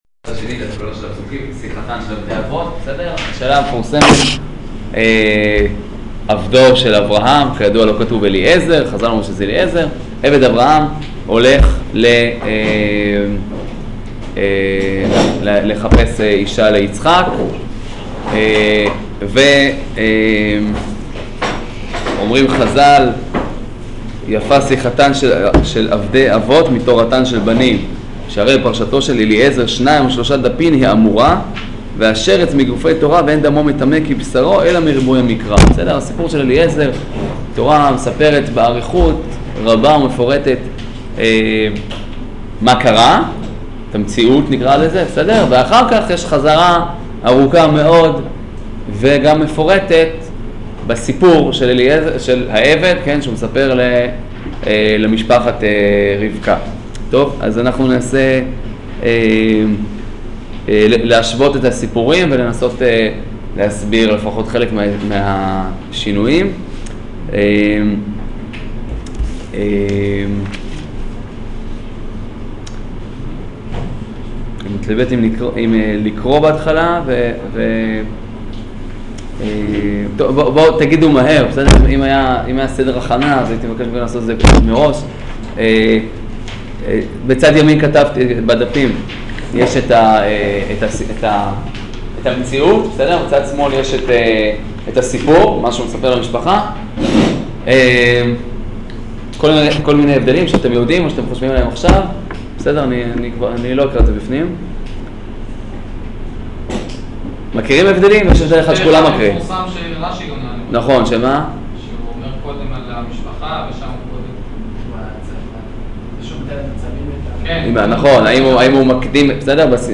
שיעור חיי שרה